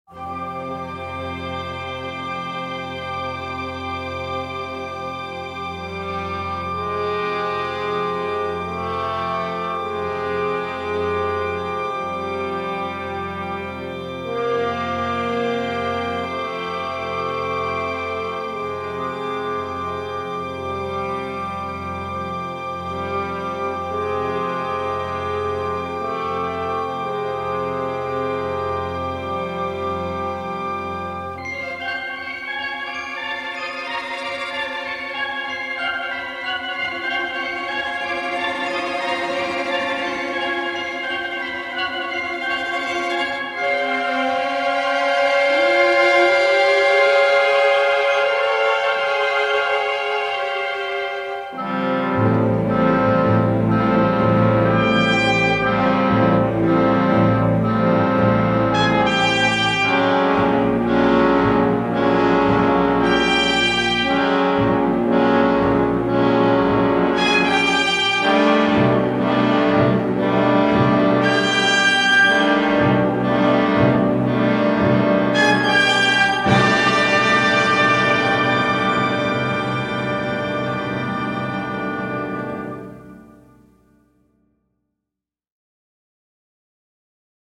anti-spectaculaire malgré sa générosité de tous les instants